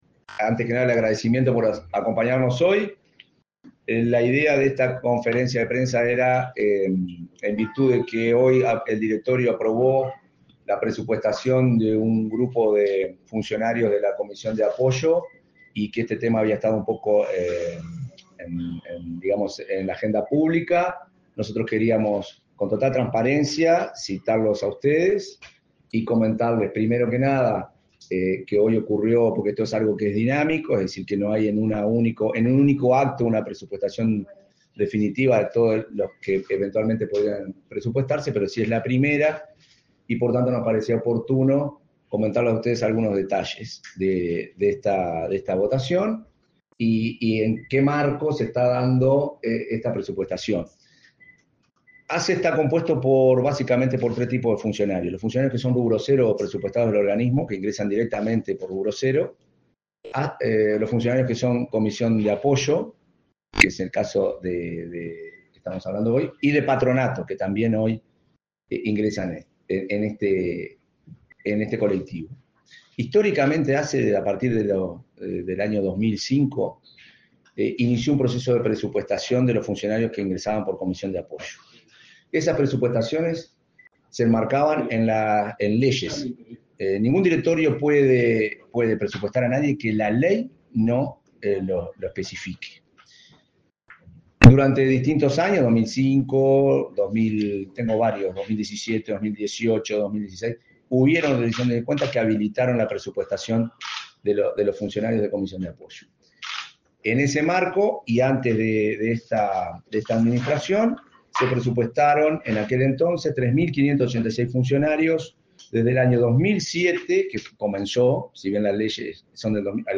Palabras del presidente de ASSE, Marcelo Sosa